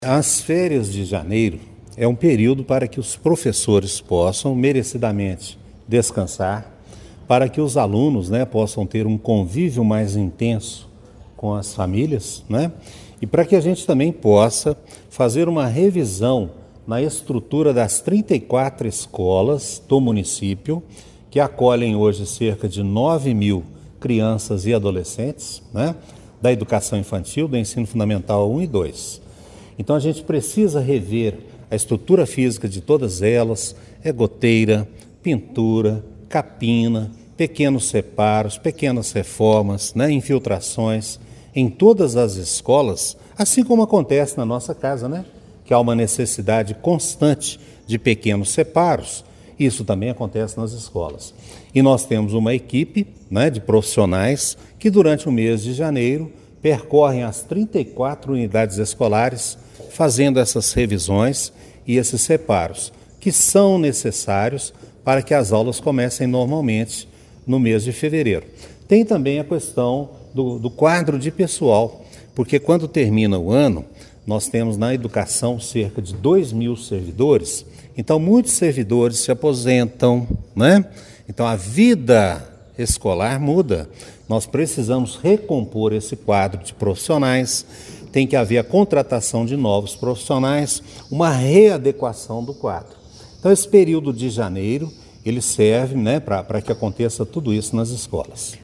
Nesta terça-feira (20), durante coletiva de imprensa no CMEI Cônego Gabriel Hugo da Costa Bittencourt, o secretário Marcos Aurélio dos Santos detalhou as ações que preparam as 34 escolas da cidade para o retorno das atividades.